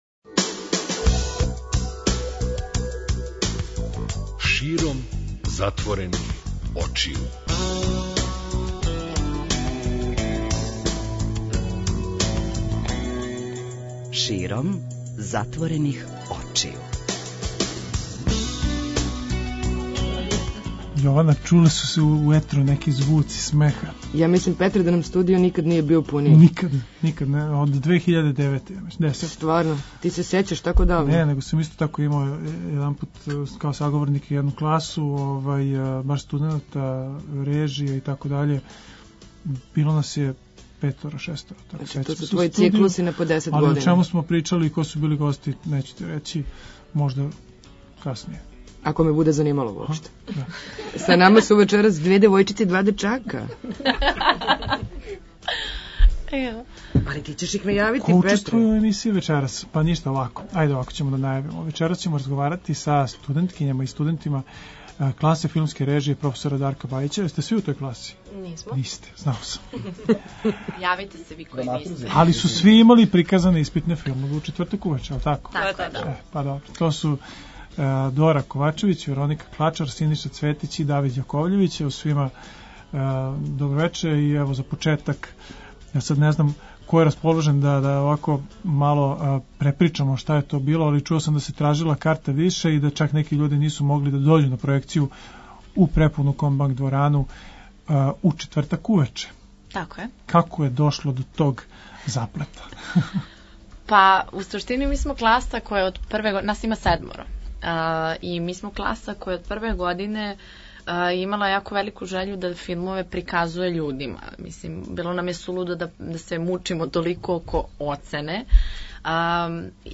Вечерас разговарамо са студентима класе филмске режије